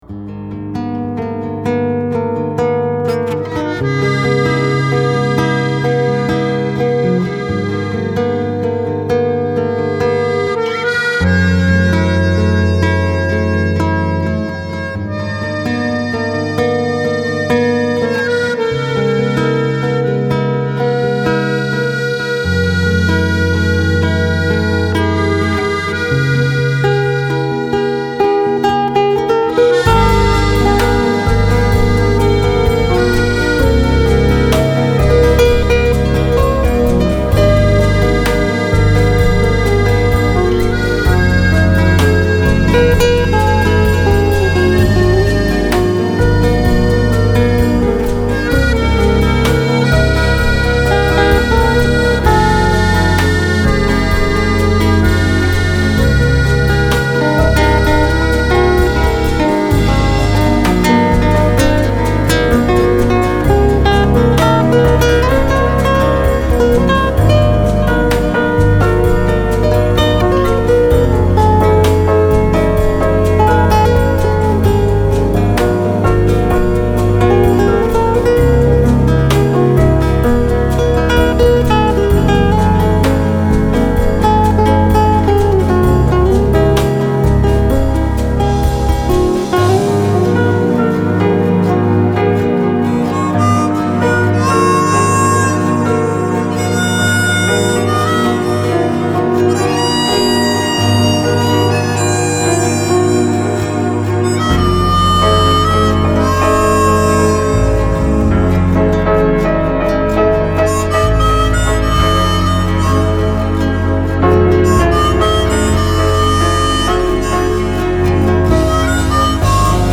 Медитативная музыка Релакс Нью эйдж New Age Музыка релакс